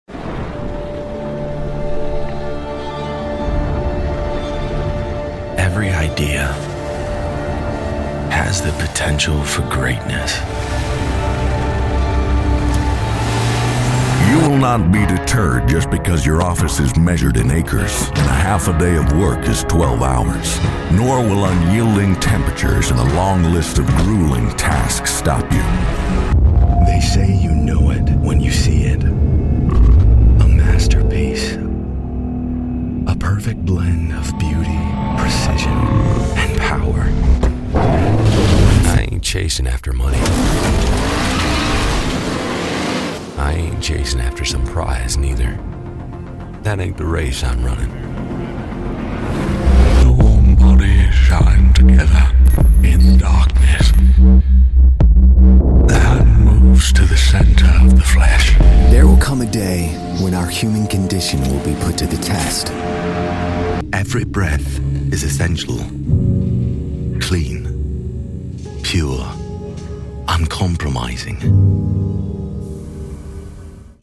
Natürlich, Unverwechselbar, Zugänglich, Vielseitig, Warm
Unternehmensvideo